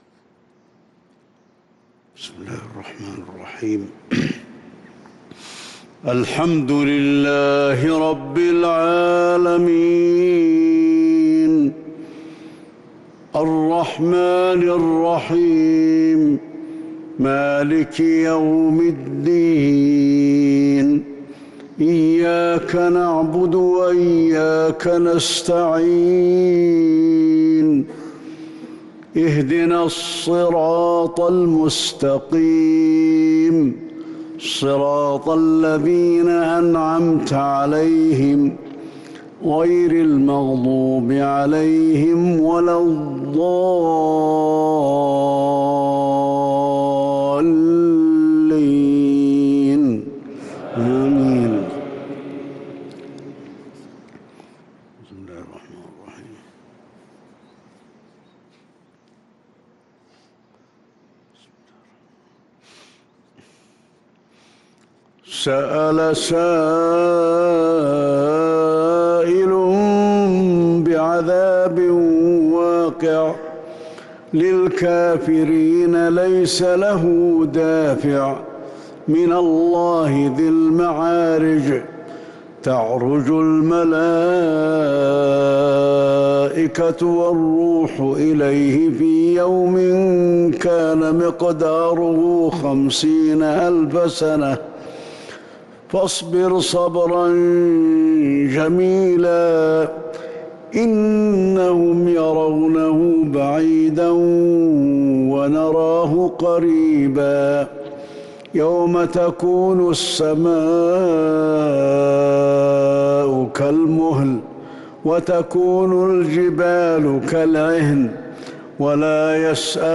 صلاة الفجر 2 شوال 1443هـ سورة المعارج كاملة | fajr prayar surah AlMa'arij 3-5-2022 > 1443 🕌 > الفروض - تلاوات الحرمين